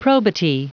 Prononciation du mot probity en anglais (fichier audio)
Prononciation du mot : probity